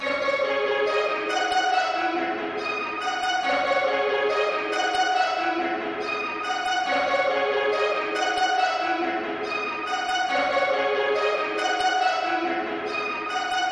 合成器
标签： 140 bpm Trap Loops Synth Loops 2.31 MB wav Key : D
声道立体声